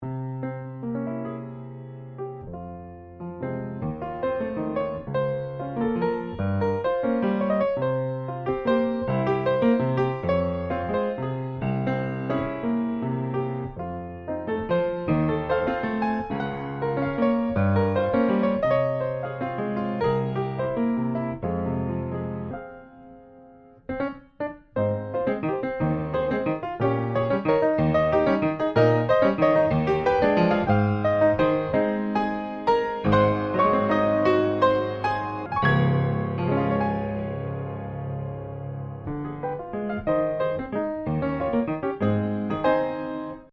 Valzer venezuelani del XIX sec.
Pianoforte
Registrazione audiophile realizzata nei mesi di agosto e settembre 2005 con microfoni e pre-amplificatore a valvole, campionamento a 96 kHz. Le tecniche di registrazione utilizzate con l'intento di creare nell'ascoltatore l'impressione di trovarsi lui stesso al posto del pianista.